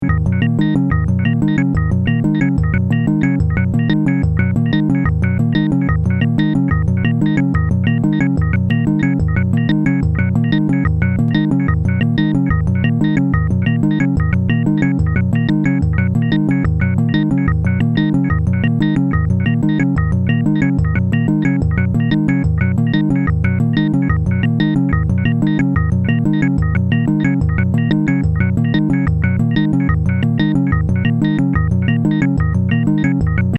Synthétiseur 8 voix polyphonique 8 Voices polyphonic keyboard synth